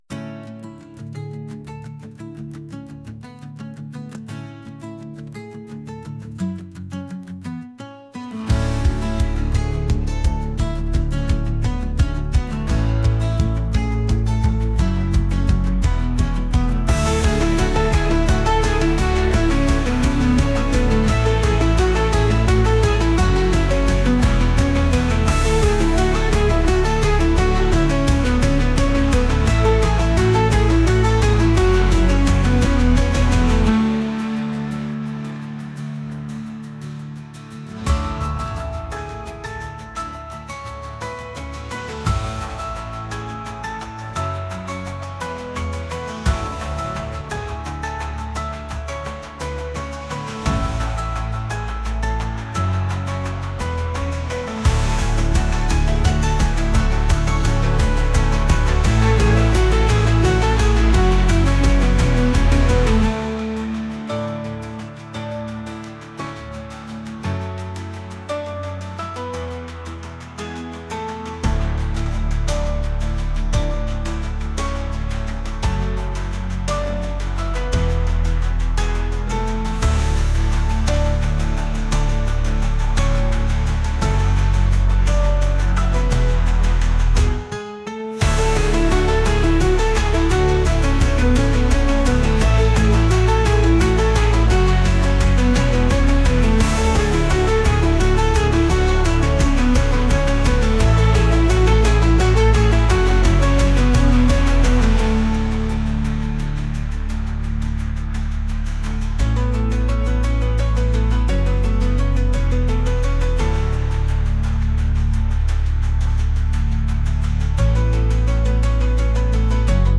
acoustic | pop | folk